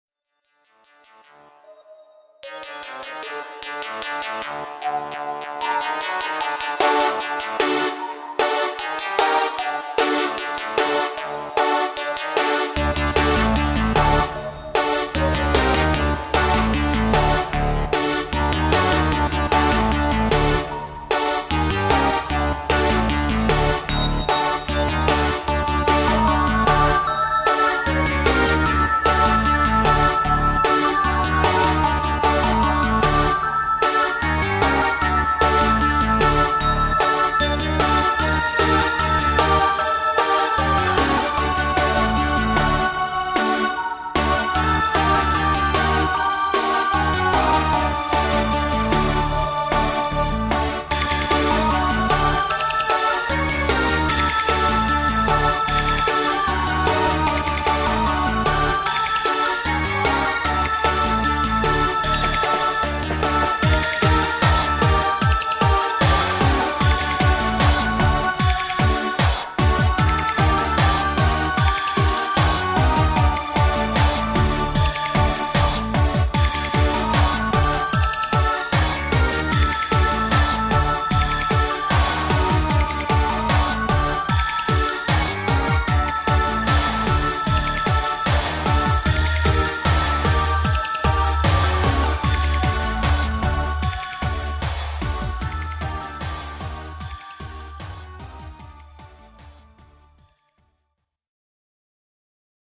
vieille qualité comme d'hab'...